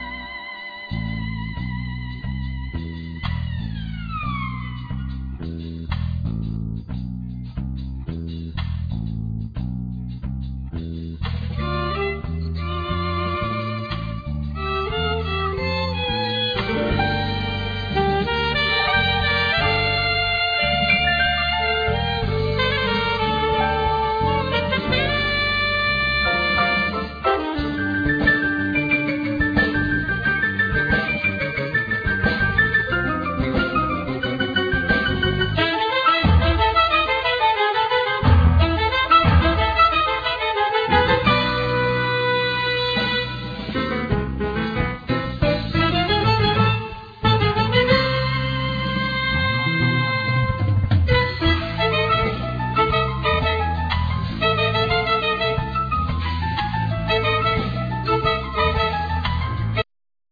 Alto & Tenor Saxophone
Viloin
Keyboards
Bass
Drums